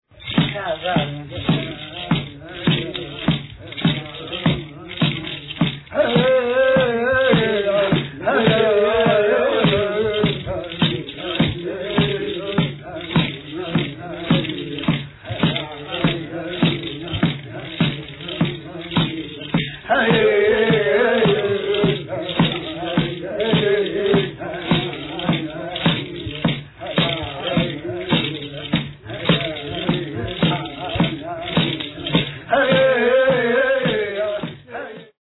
Mourning Song - 1:26